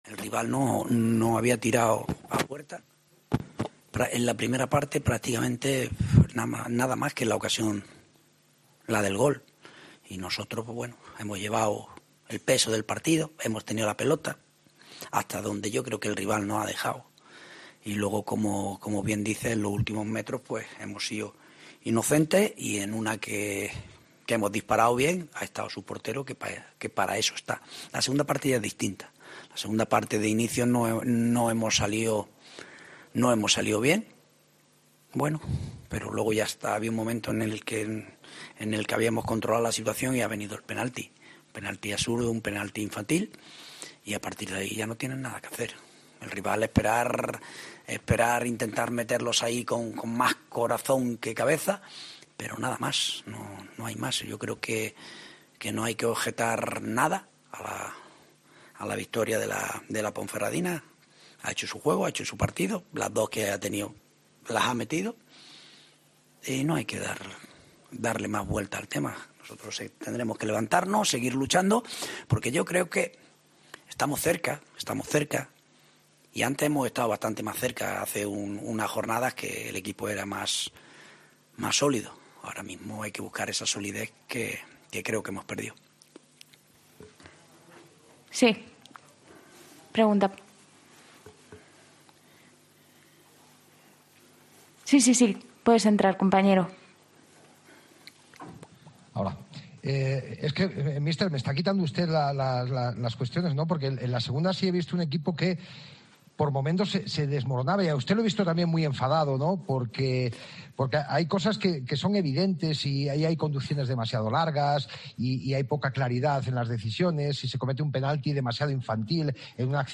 Postpartido Ponferradina - Alcorcón (2-0)